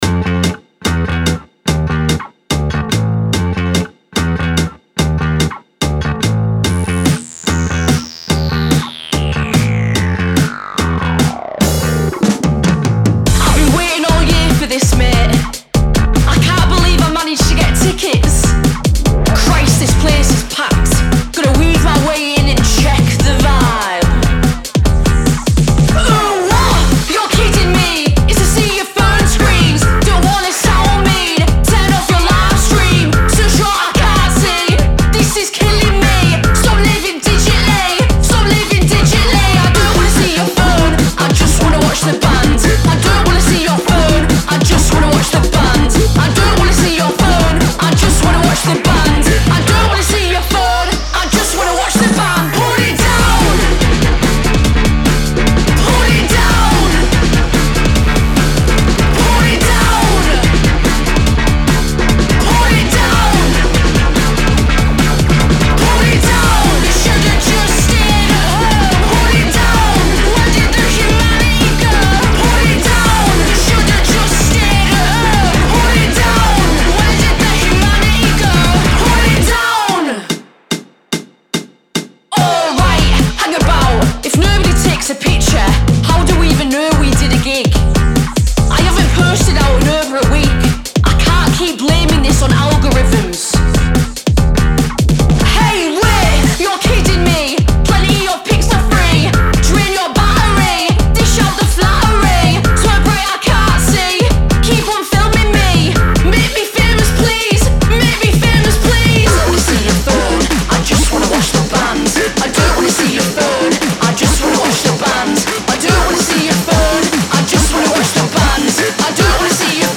spoken word
a highly relatable party track
decidedly light-hearted